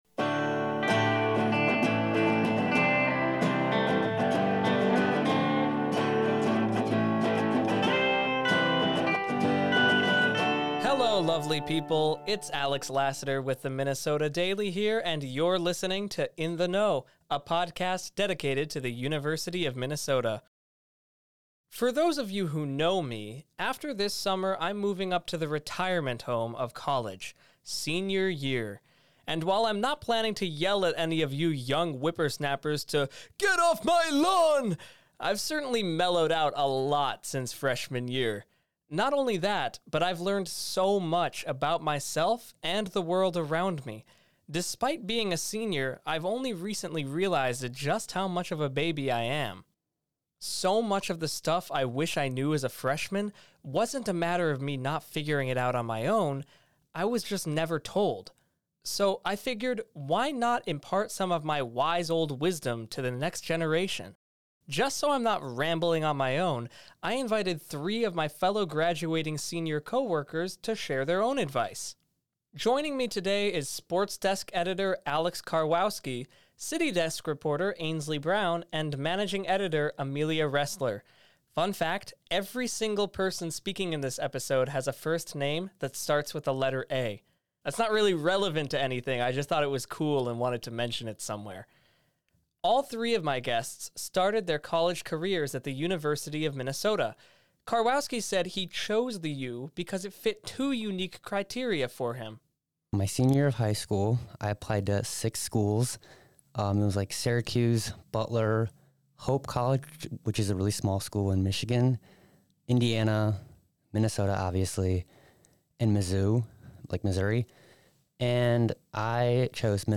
In the final summer episode, University of Minnesota seniors share personal insights and advice for incoming freshmen, reflecting on their growth through the college experience.